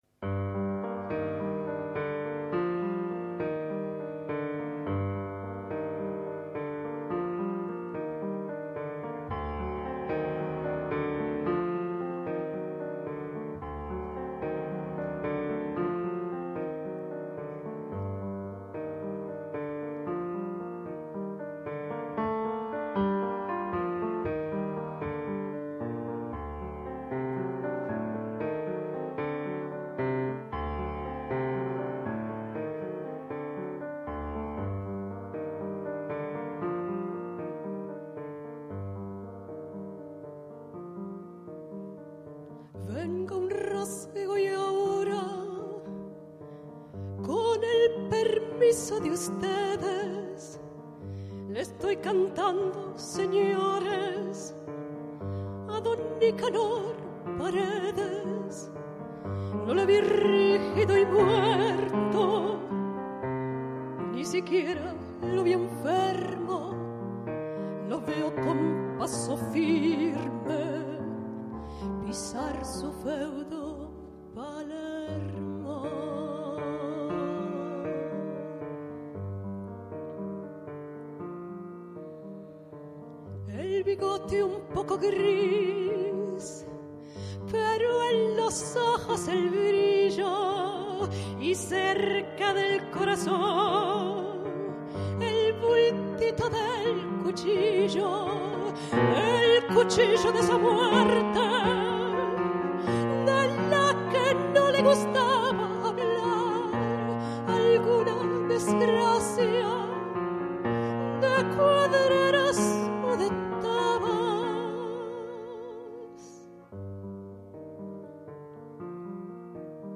pianoforte.
live recording